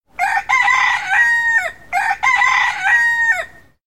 جلوه های صوتی
دانلود صدای قوقولی قوقو خروس از ساعد نیوز با لینک مستقیم و کیفیت بالا
برچسب: دانلود آهنگ های افکت صوتی انسان و موجودات زنده